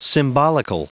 Prononciation du mot symbolical en anglais (fichier audio)
Prononciation du mot : symbolical